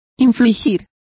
Complete with pronunciation of the translation of impose.